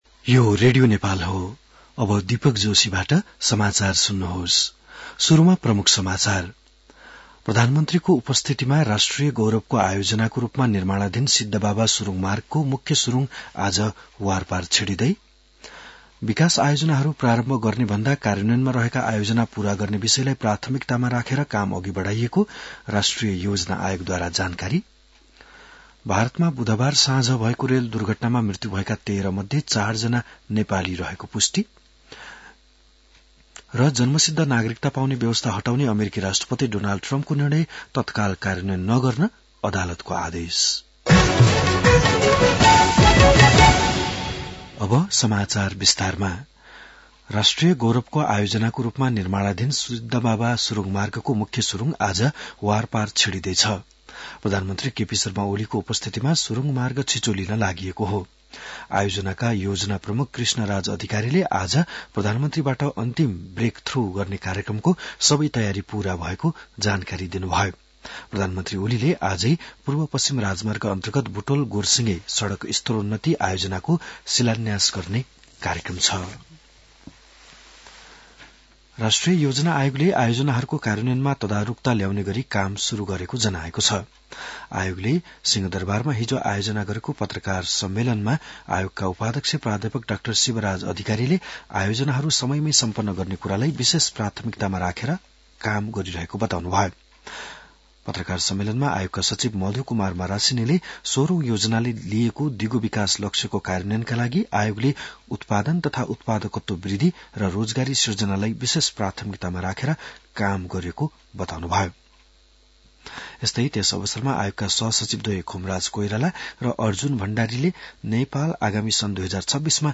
बिहान ९ बजेको नेपाली समाचार : १२ माघ , २०८१